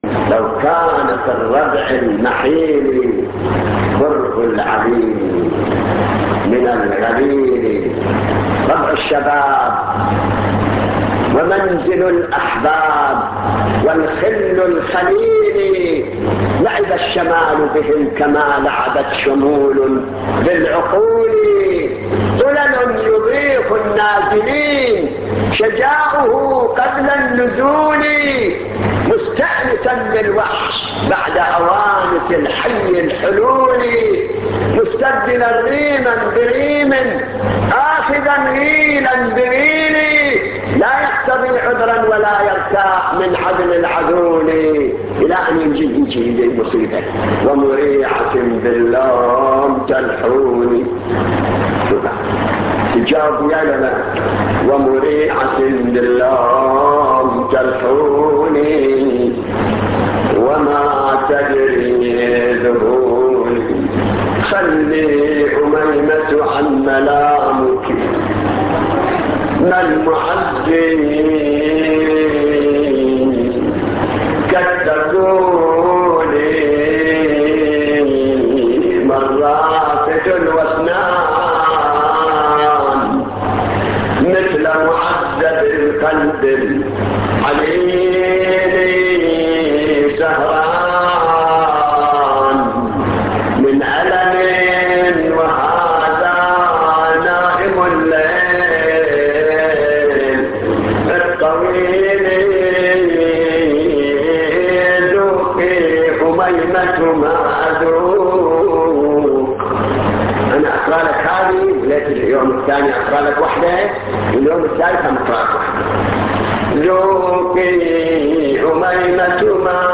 نواعي حسينية13